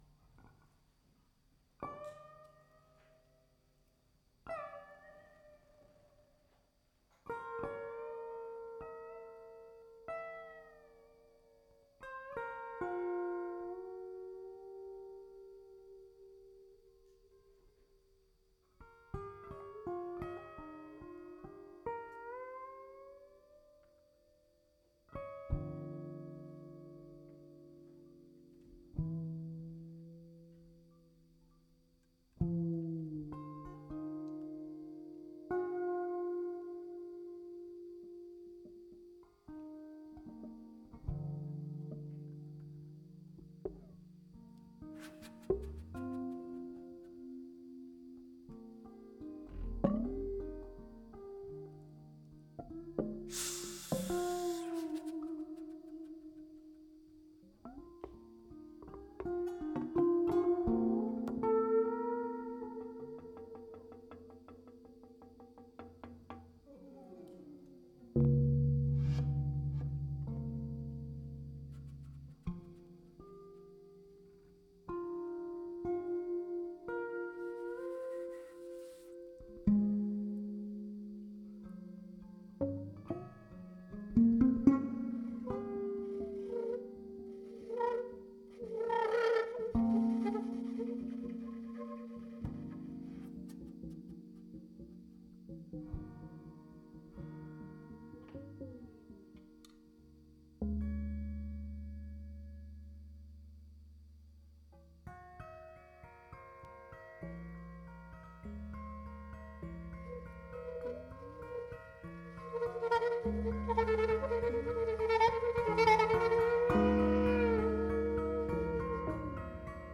Danish saxophonist